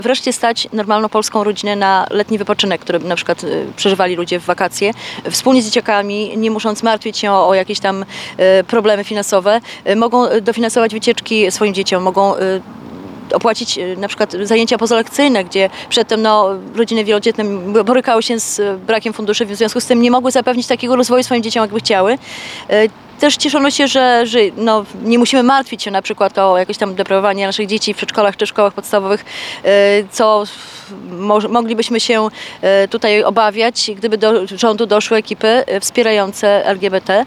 podczas konferencji prasowej w Suwałkach